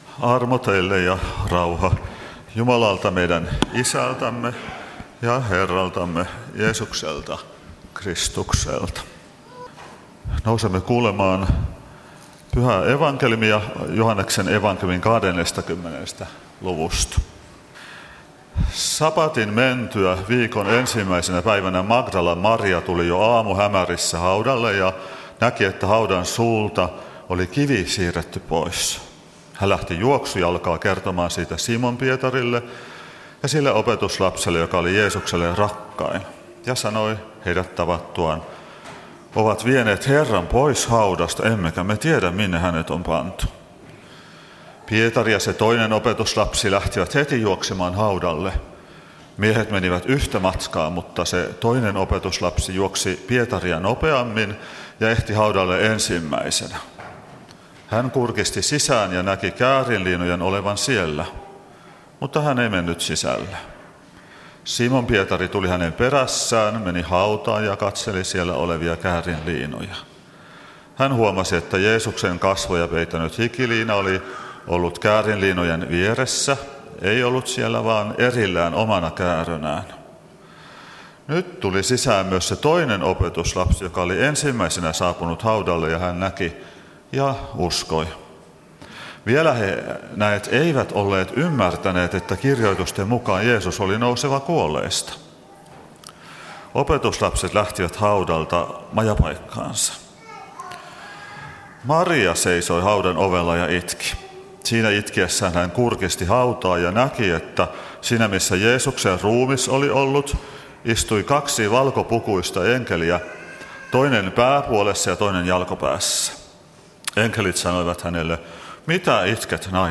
Kokoelmat: Tampereen Luther-talo